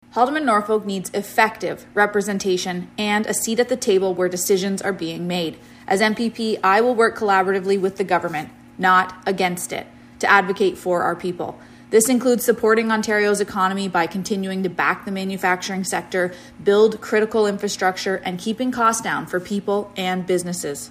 We are reaching out to candidates to come in for an interview about why they want to serve as Haldimand-Norfolk’s Member of Provincial Parliament.